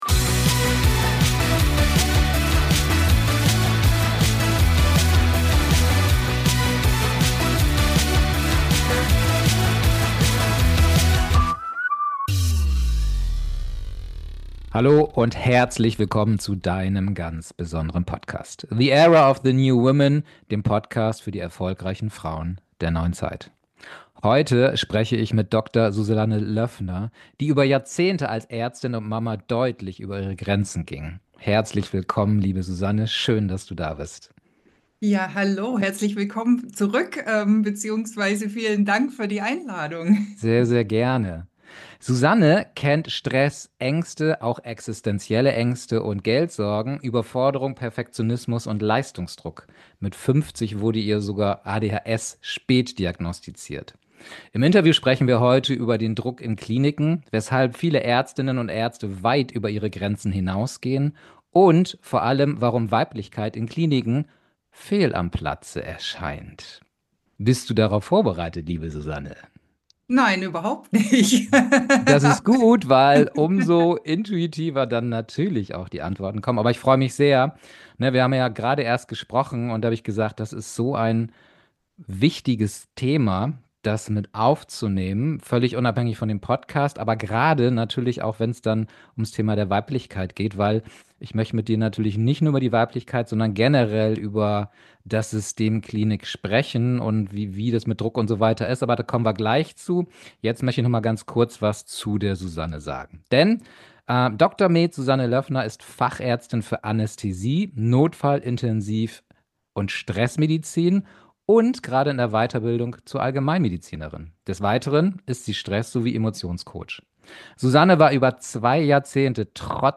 #049 Warum in der Medizin Weiblichkeit keinen Platz findet. Das Interview